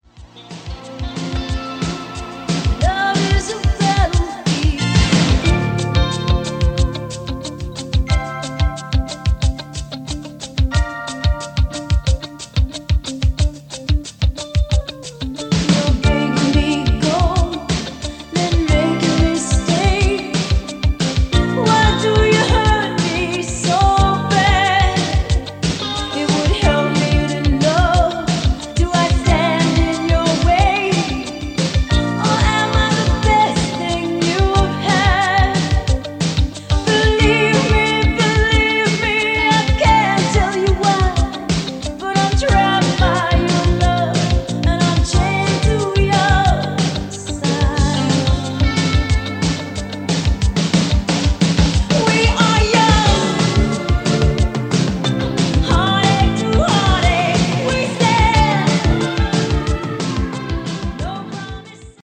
The built quality is excellent as well as the sound quality.
Below is a test recording made with the KD-D4 and played back by it: